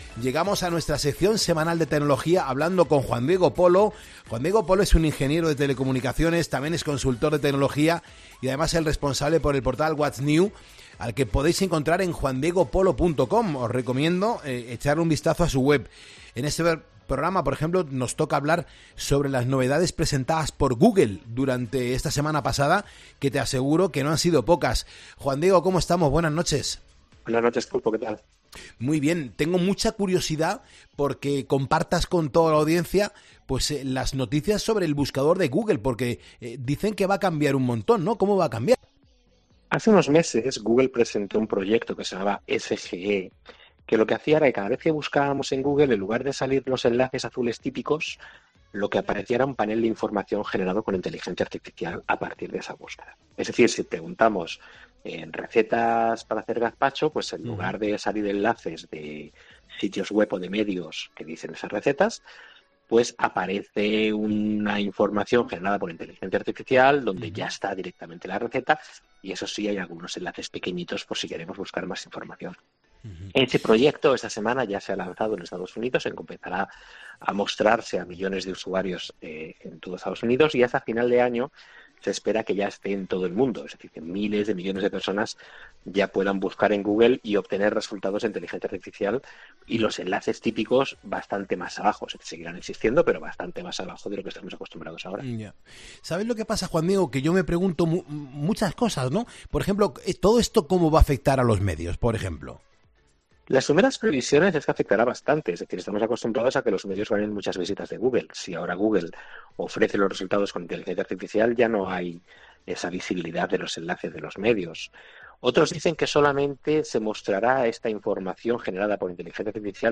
En nuestra sección semanal de tecnología hablamos con nuestro experto